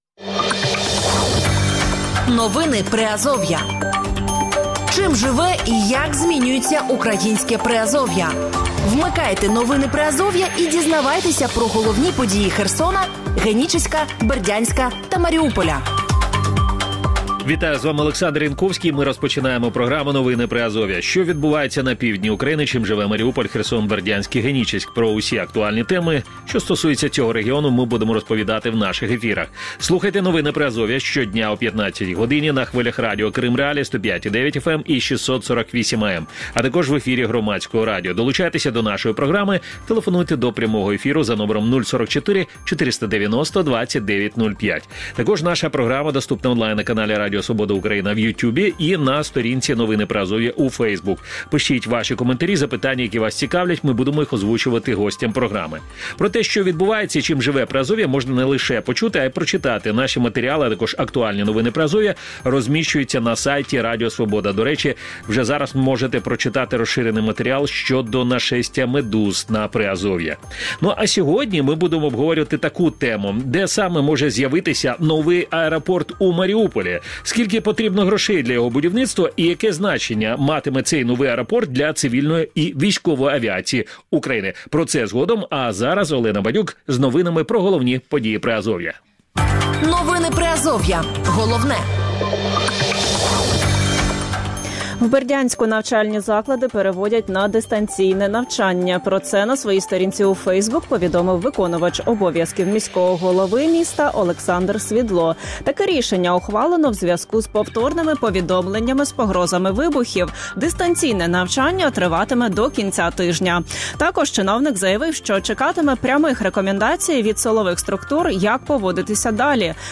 Гості ефіру